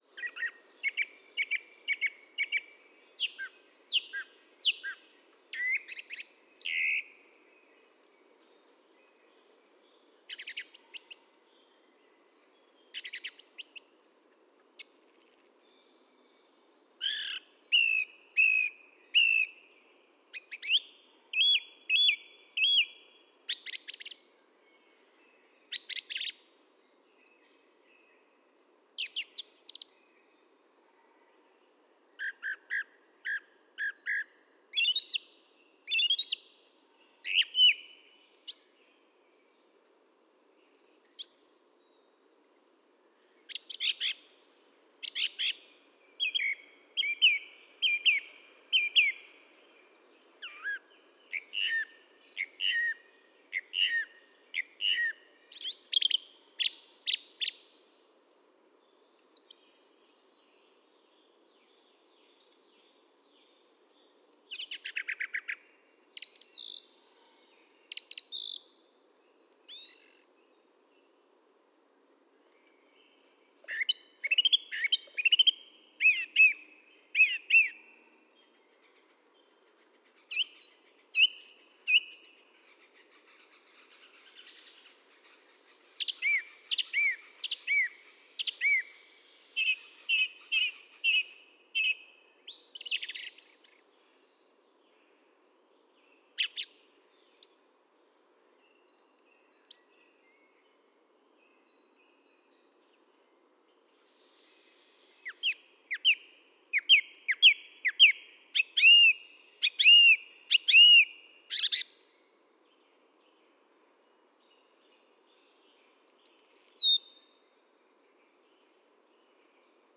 PFR10698, 1-11, 150325, Song Thrush Turdus philomelos, song
north-western Saxony, Germany, Telinga parabolic reflector